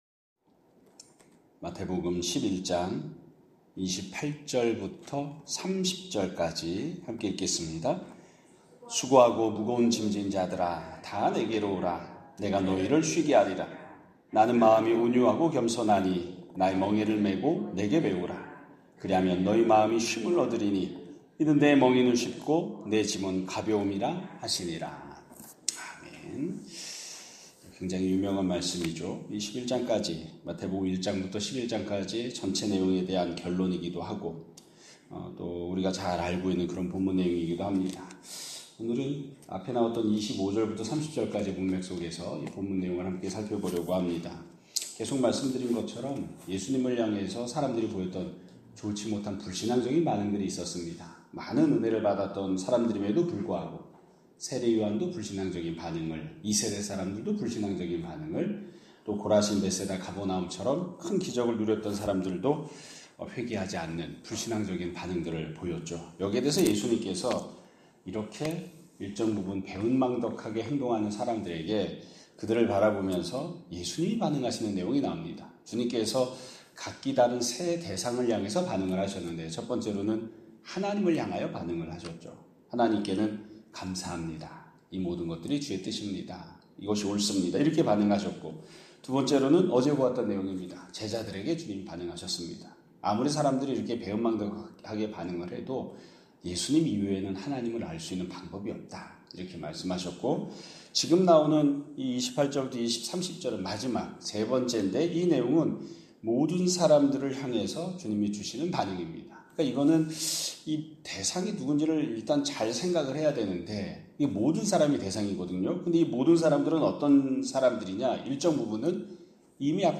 2025년 9월 3일 (수요일) <아침예배> 설교입니다.